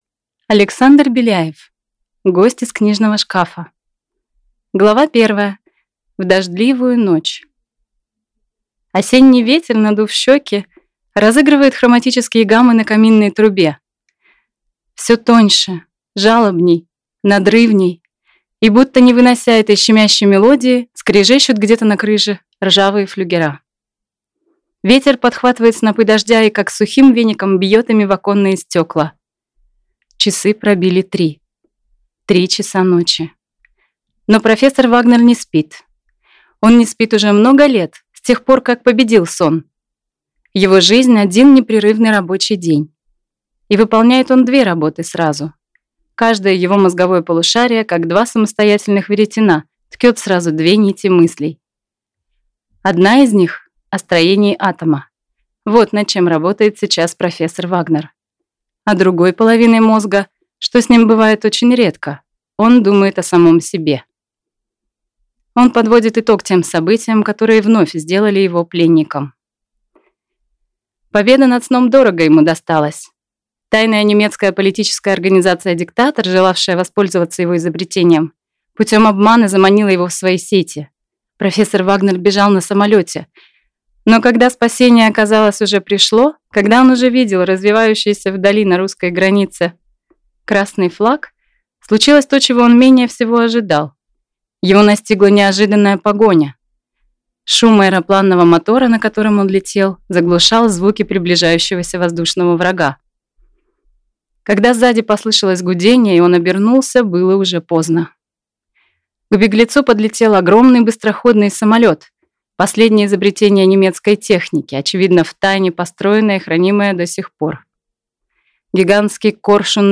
Аудиокнига Гость из книжного шкафа | Библиотека аудиокниг
Прослушать и бесплатно скачать фрагмент аудиокниги